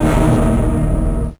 55bf-orc08-d#2.wav